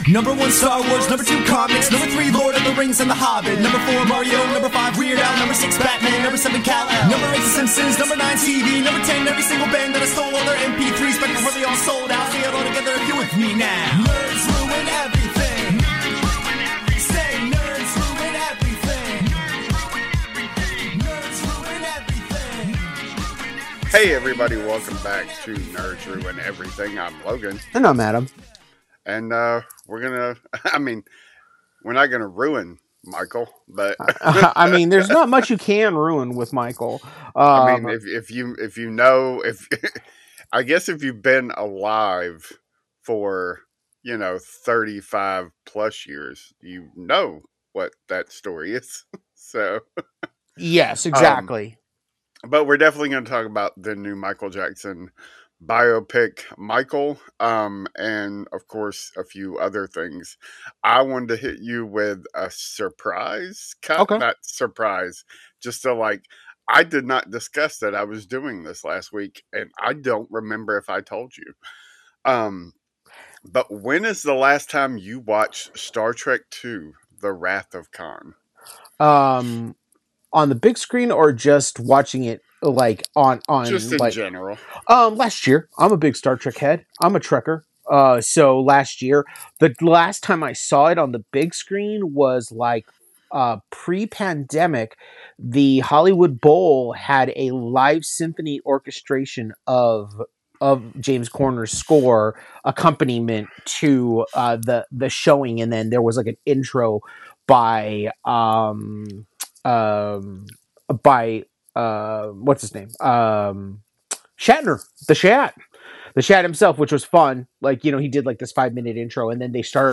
two nerds